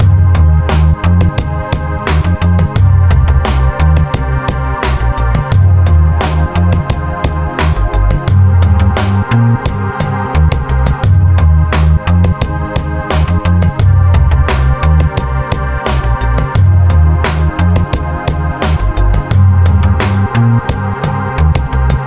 ambient_loop1
ambient loop, using sytrus and synth1
ambient_loop1.ogg